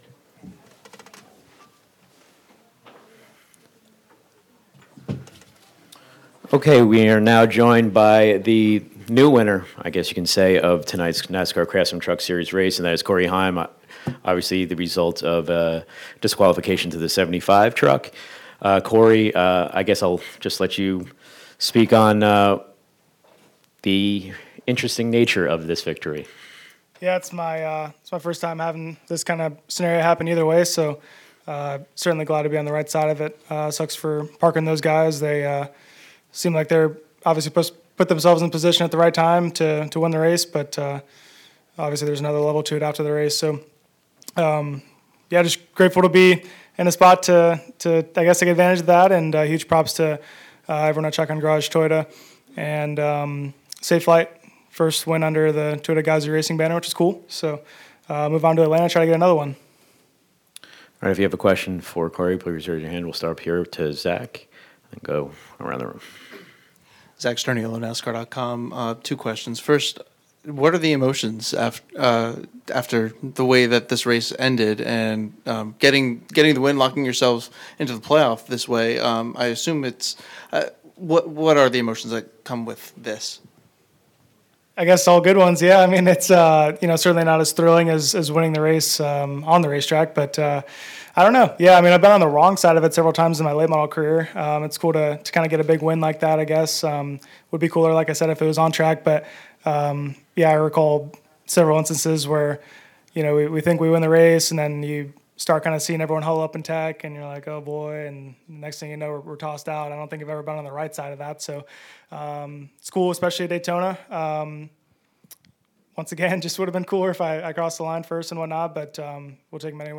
Interview:
Corey Heim (No. 11 TRICON Garage Toyota) – CRAFTSMAN Truck Series Race Winner –